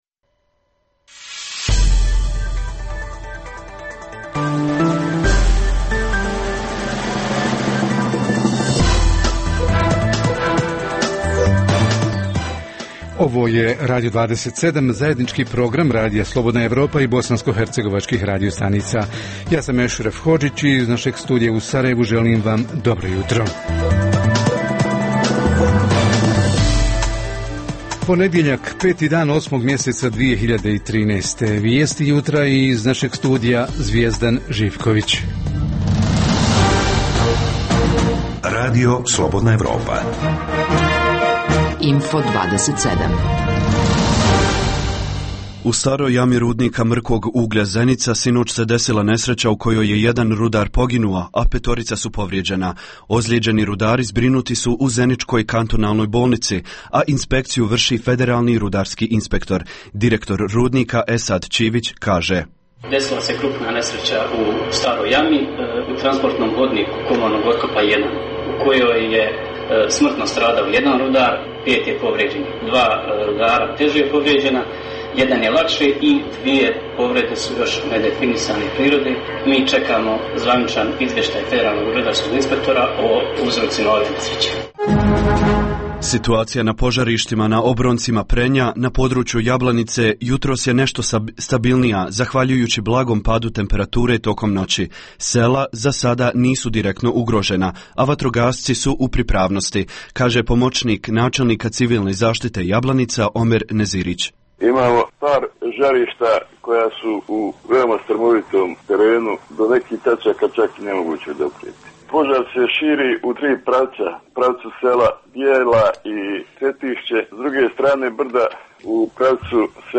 - Info plus: Požar koji bukti na Draganjskoj planini u općini Jablanica ugrožava 100-ak stanovnika obližnjih sela i širi se prema općini Konjic – s lica mjesta javlja se naš reporter.